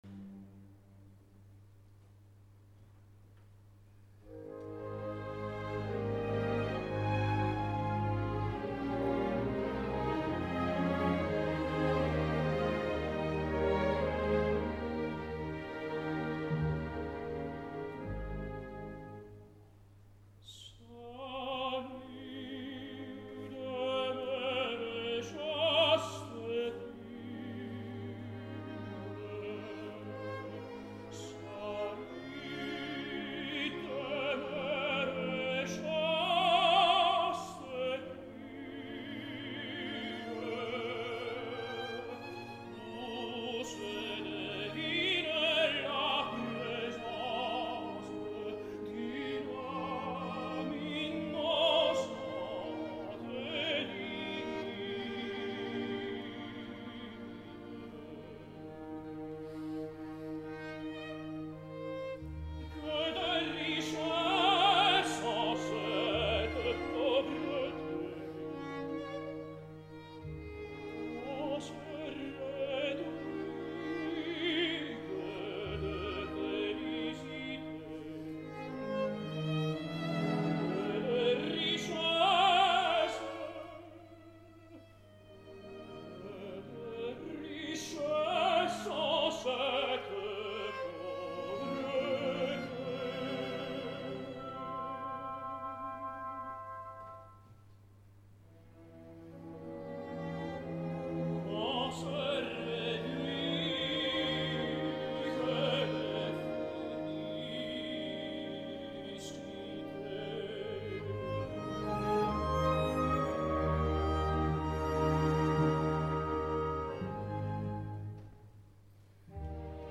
Faust: Vittorio Grigolo
Royal Opera House, Covent Garden de Londres
El registre greu és insuficient i tan sols l’agut és generós, encara que no sempre està emès amb una projecció prou encertada.